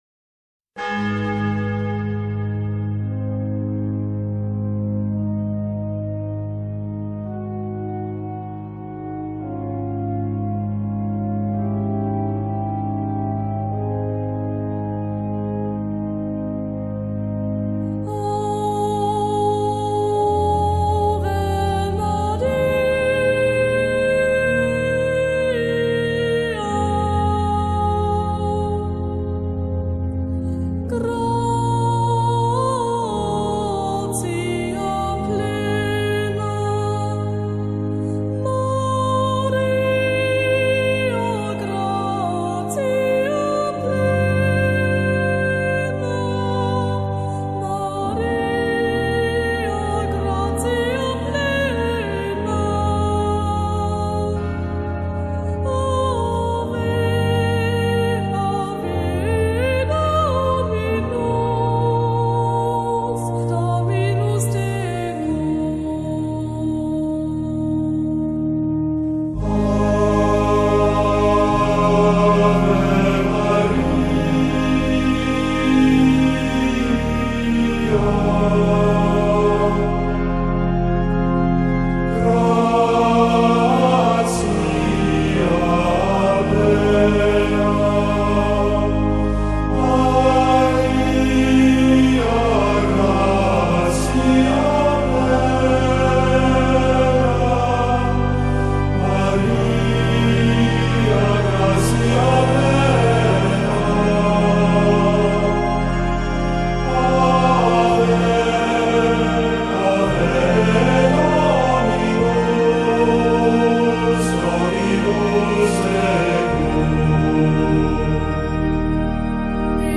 KAFoYC5vpZ7_ave-maria-gregorian.mp3